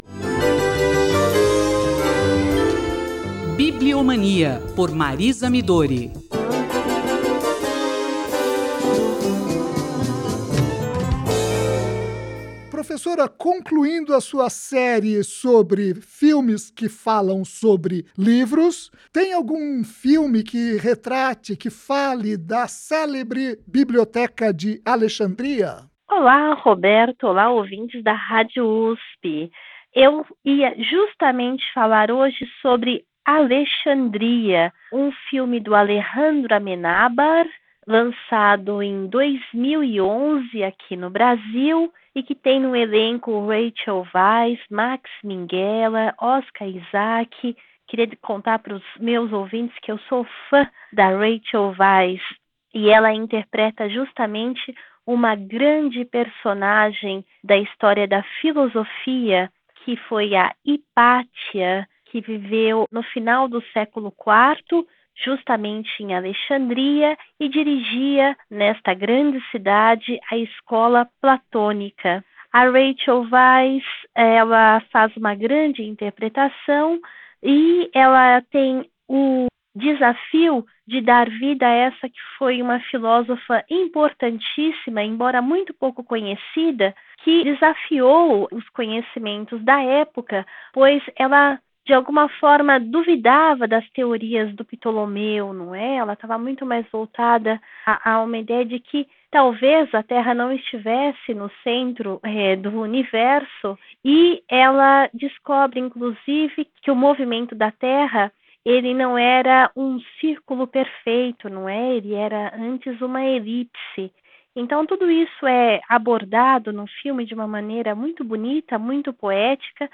transmitida no dia 26 de julho de 2019 pela Rádio USP (93,7 MHz).